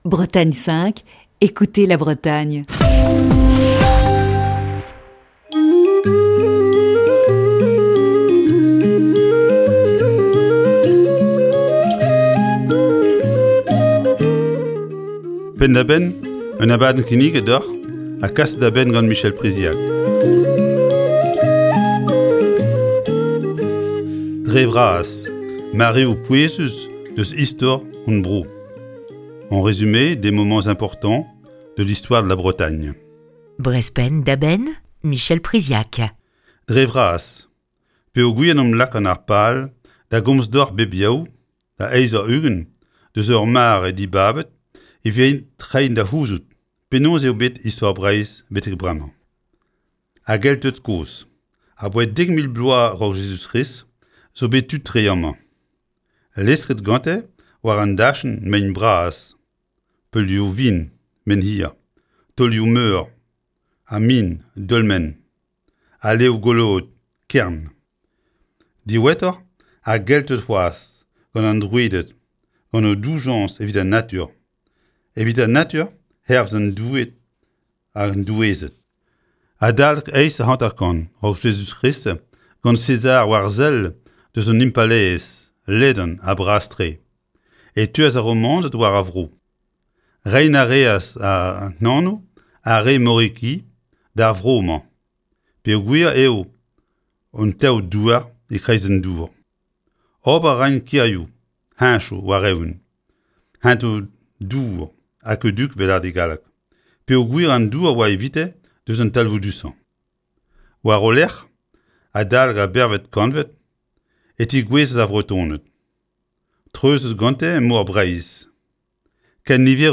(Chronique diffusée le 12 septembre 2019).